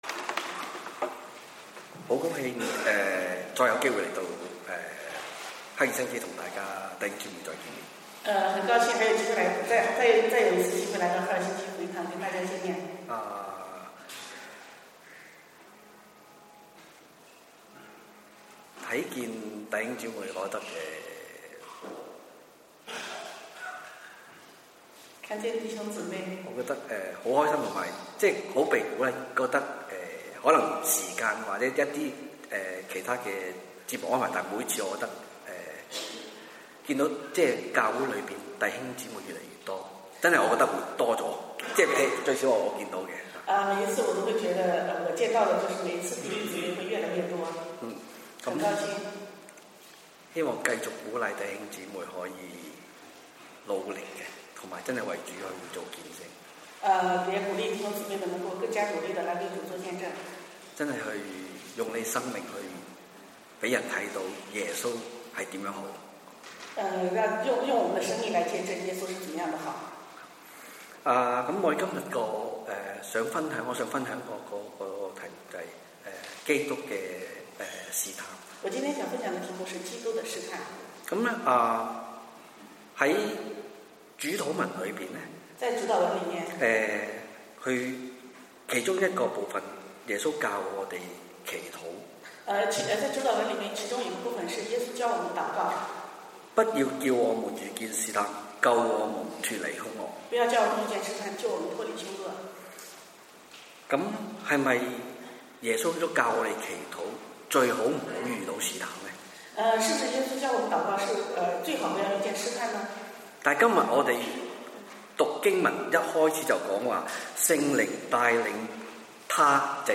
(粤语翻国语)
主日讲道音频